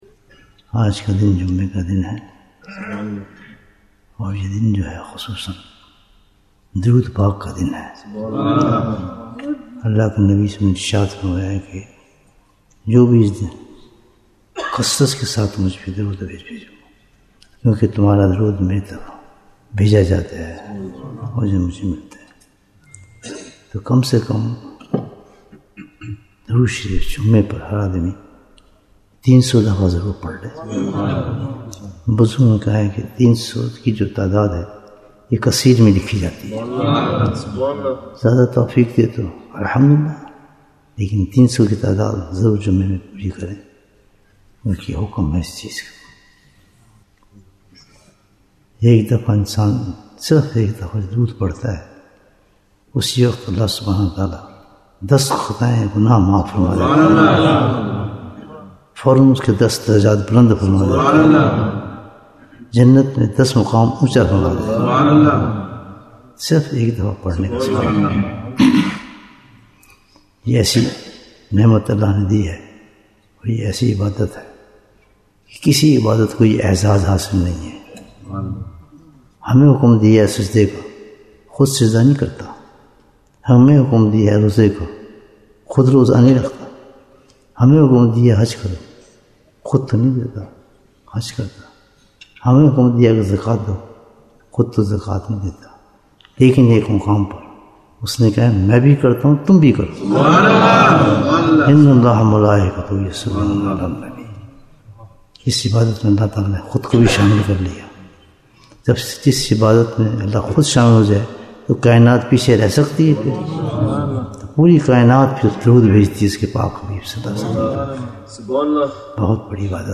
Bayan, 7 minutes